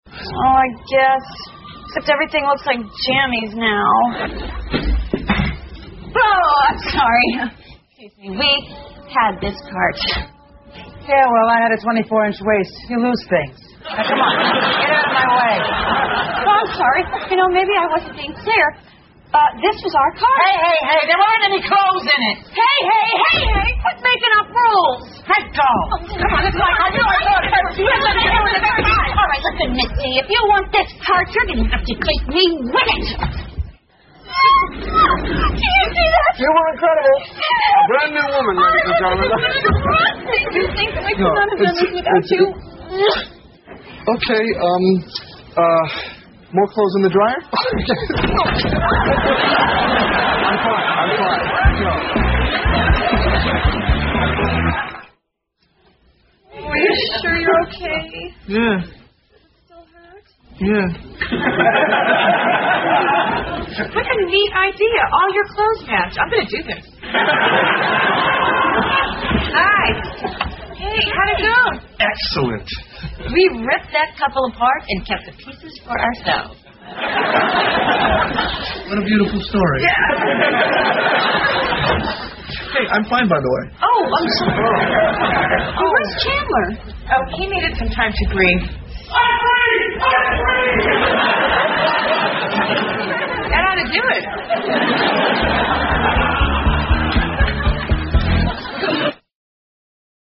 在线英语听力室老友记精校版第1季 第60期:洗衣服(13)的听力文件下载, 《老友记精校版》是美国乃至全世界最受欢迎的情景喜剧，一共拍摄了10季，以其幽默的对白和与现实生活的贴近吸引了无数的观众，精校版栏目搭配高音质音频与同步双语字幕，是练习提升英语听力水平，积累英语知识的好帮手。